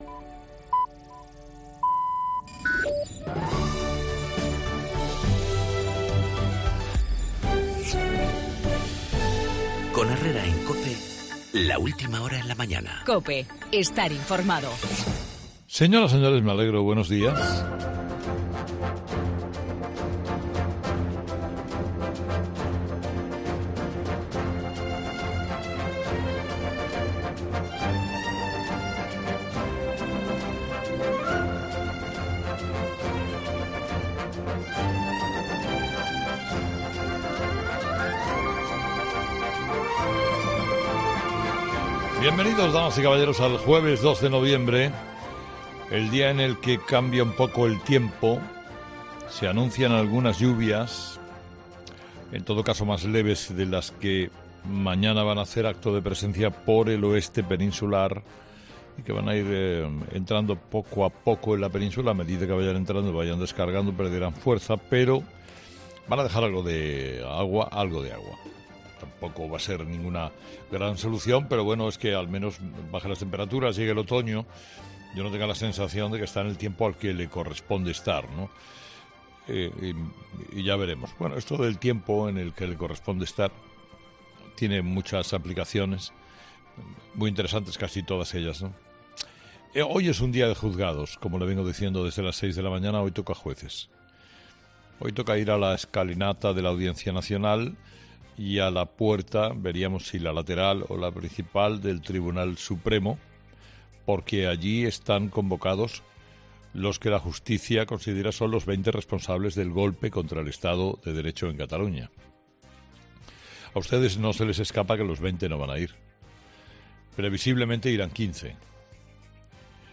Monólogo de las 8 de Herrera
La marcha a Bruselas del presidente destituido de Cataluña, en el editorial de Carlos Herrera